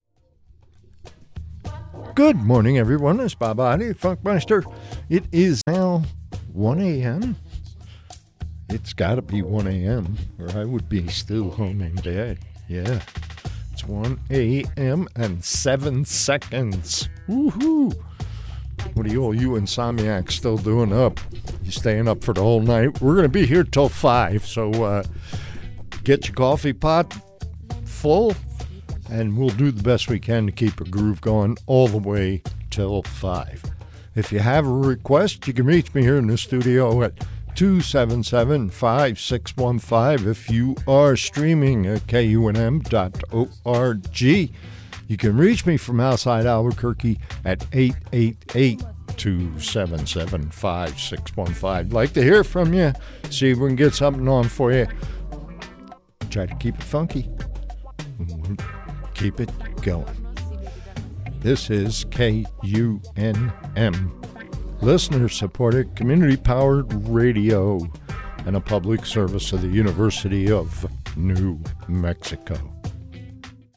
Complementing the musical cuts, he kept his nighttime listeners tuned in with a sweet and sassy voice that cut through the night skies like a great horned owl soaring toward the stars.